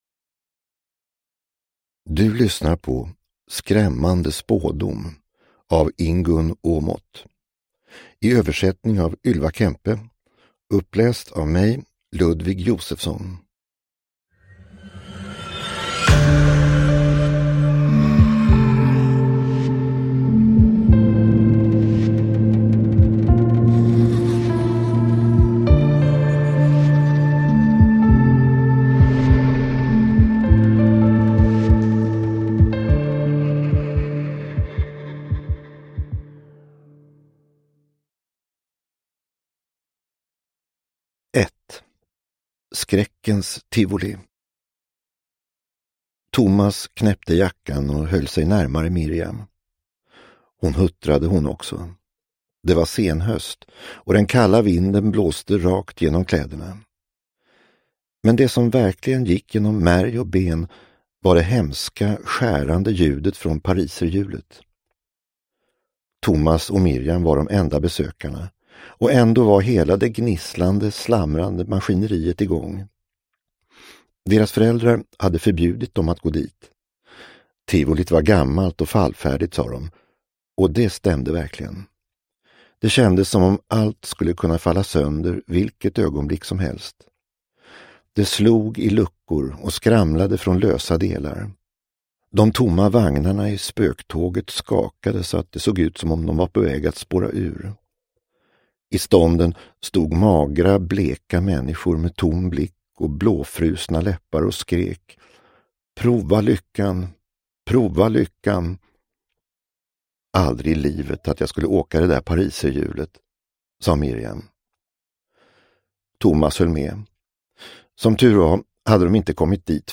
Skrämmande spådom – Ljudbok – Laddas ner